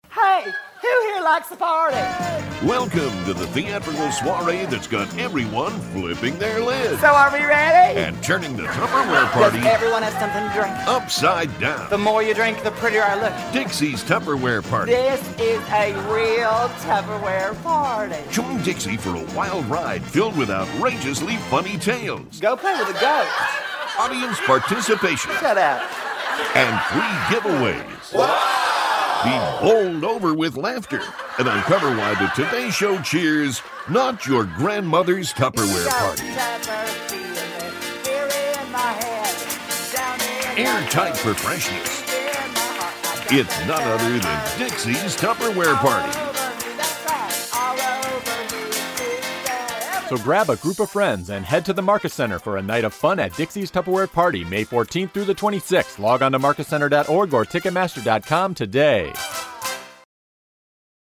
Dixie’s Tupperware Party Radio Commercial